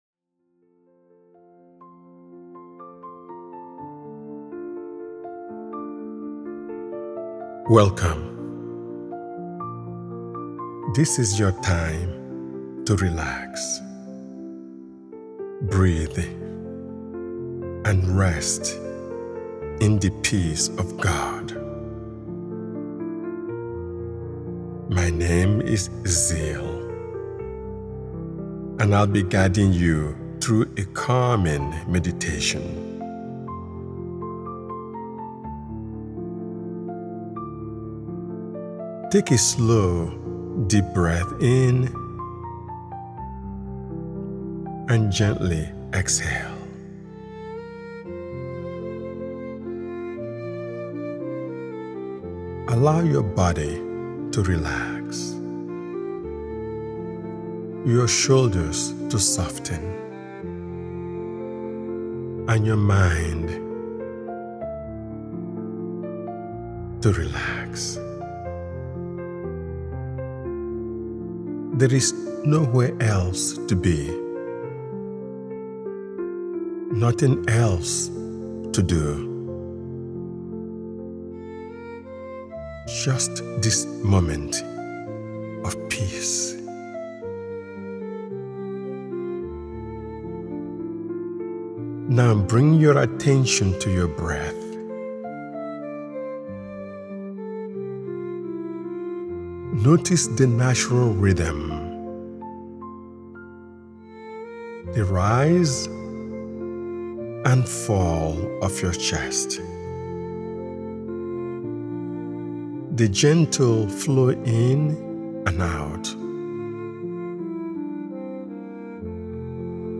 Peace in His Presence: Inspired by Philippians 4:6–7 is a scripture-centered guided meditation designed to help you release anxiety and rest in God’s calming grace.